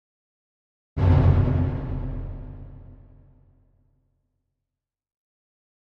Drum Deep Symphonic - Orchestra Drum Single Hit - Pulsation